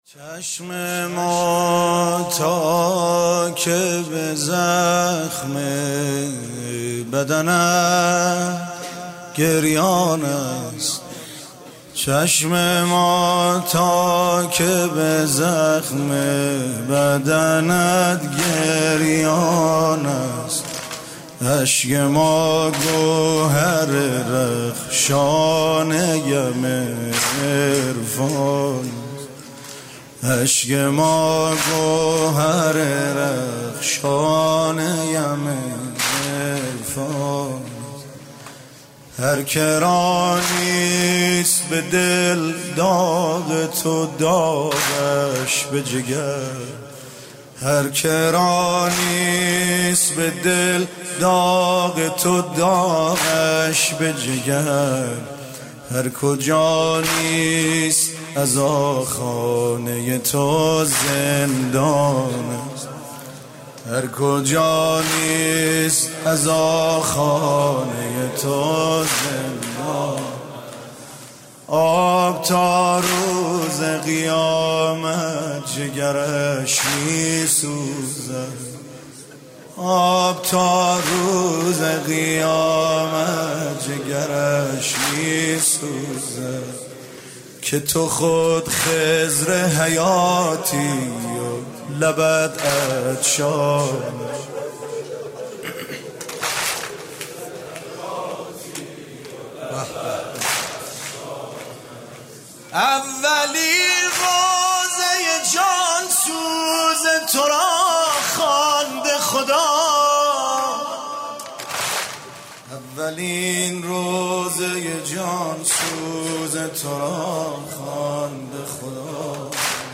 شهادت امام صادق علیه‌السلام
music-icon واحد: هرکجانیست عزاخانه ی تو زندان است